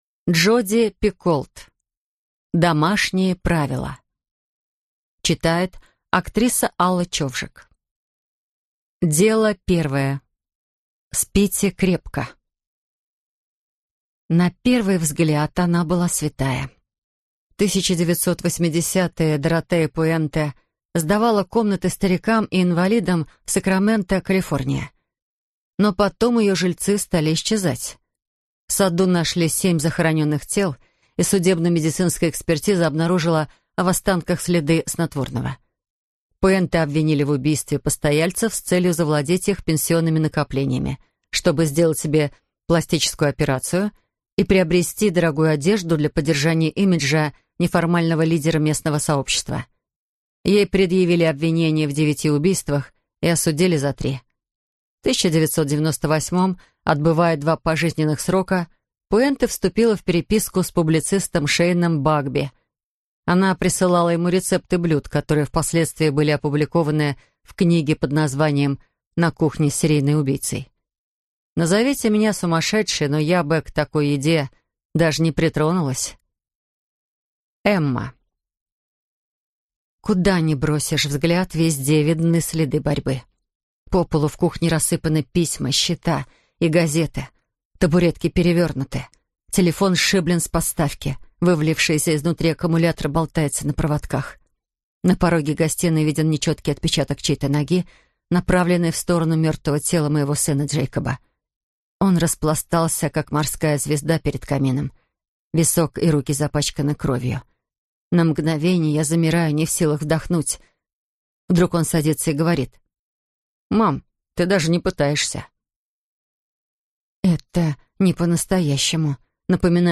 Аудиокнига Домашние правила | Библиотека аудиокниг